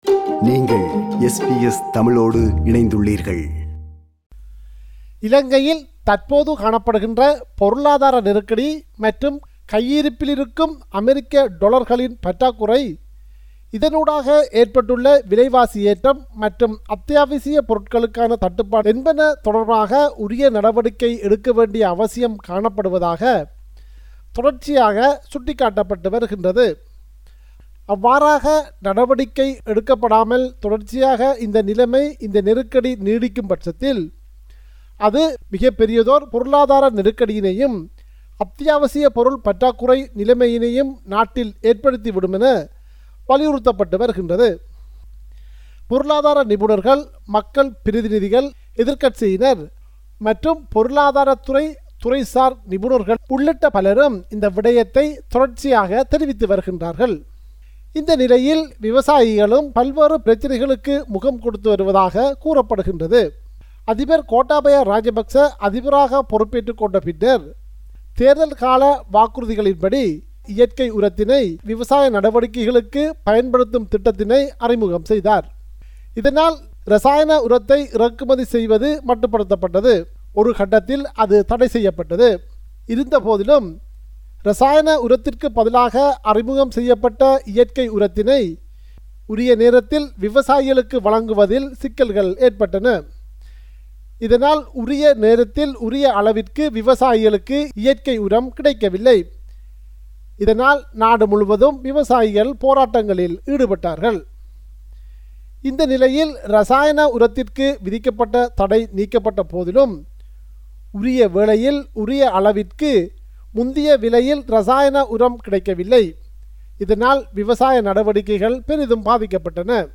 Listen to SBS Tamil at 8pm on Mondays, Wednesdays, Fridays and Sundays on SBS Radio 2.